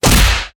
Indiana Jones Punch.wav